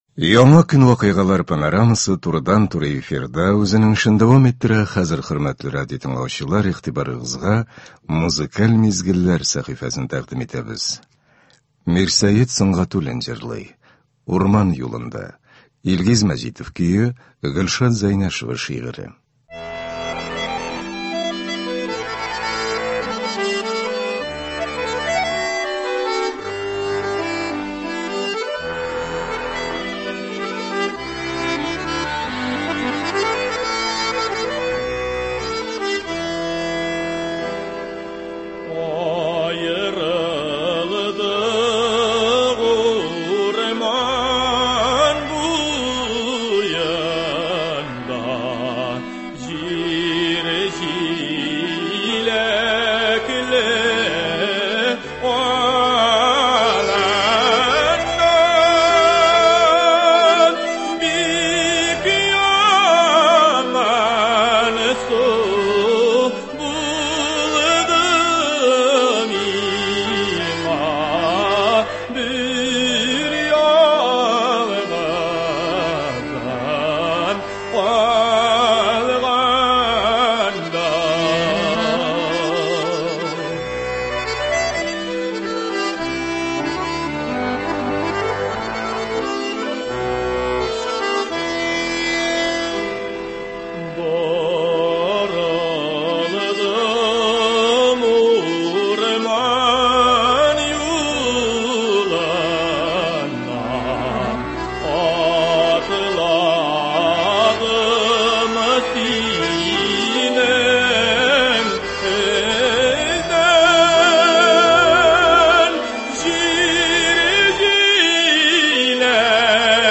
Бүгенге иртәнге концертта яраткан җырларыбыз яңгырый.